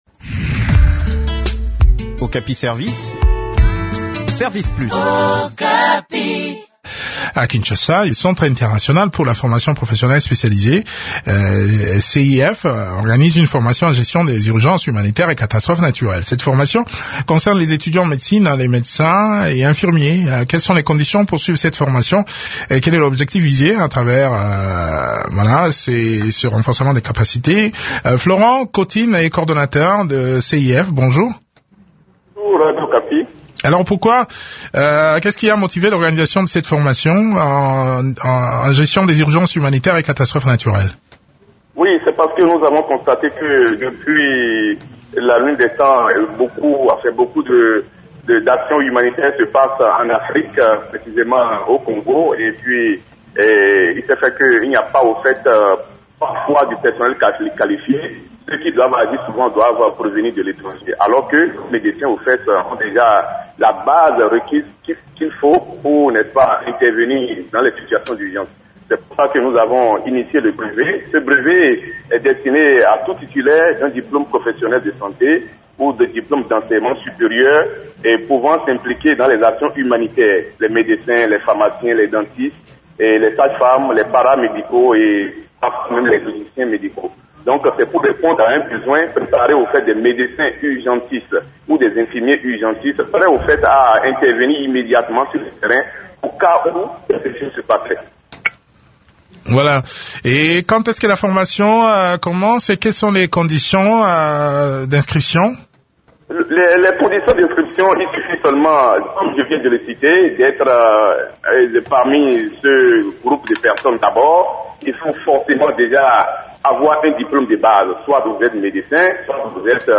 Les précisons sur cette formation dans cet entretien